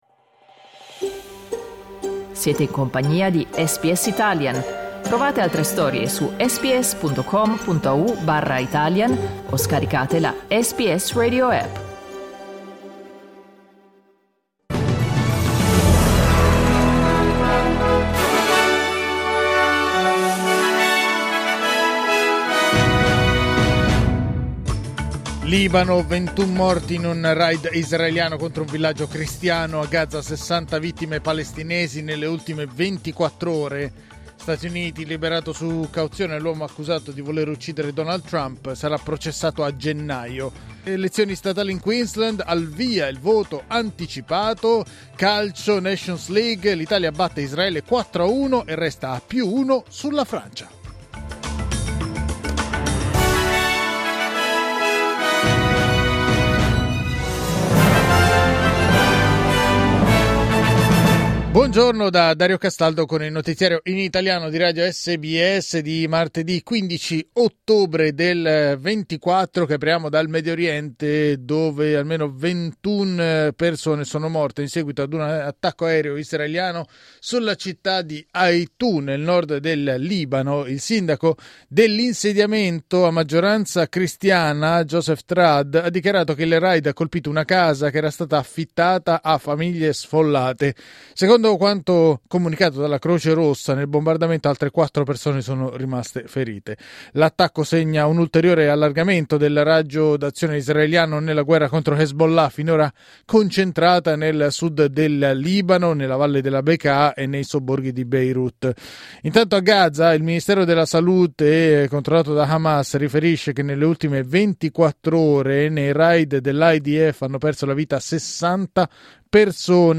Giornale radio martedì 15 ottobre 2024
Il notiziario di SBS in italiano.